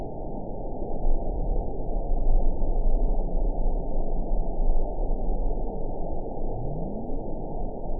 event 922770 date 03/30/25 time 19:59:20 GMT (2 months, 2 weeks ago) score 9.10 location TSS-AB04 detected by nrw target species NRW annotations +NRW Spectrogram: Frequency (kHz) vs. Time (s) audio not available .wav